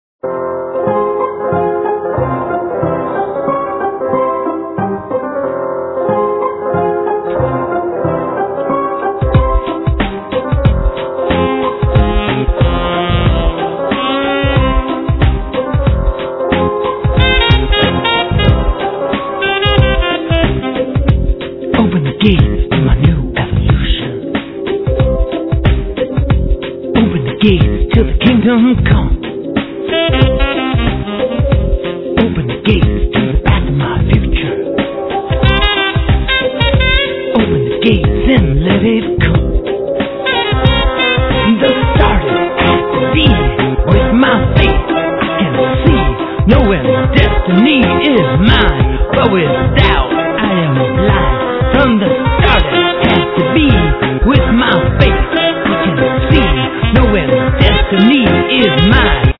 Lead vocals, Ocarina
Guitar, Vocals
Bass, Percussions, Didgeridoo
Saxophone
Flute